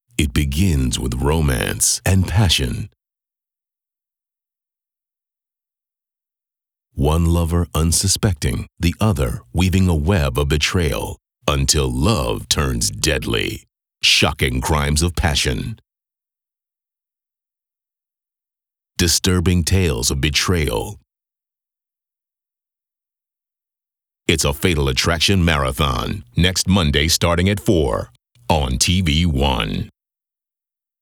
FA Marathon_Starting Next Monday30_VO.wav